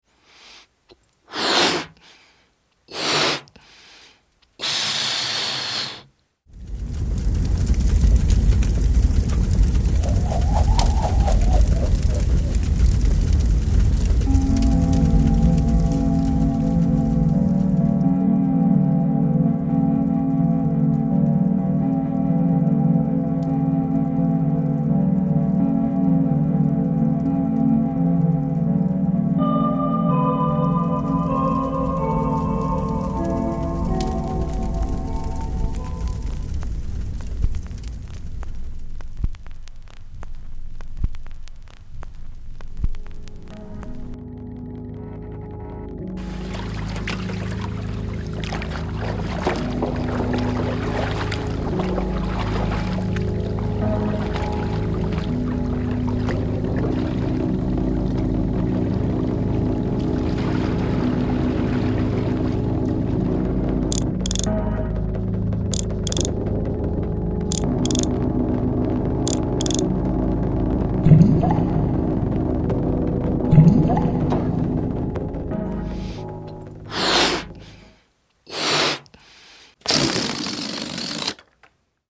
• ballon opblazen
• knetterend haardvuur
• touw slag
• wind
• peddel van een kano in het water
• rivier
• waterdruppel
• ballon die leegloopt
Soundscape-.mp3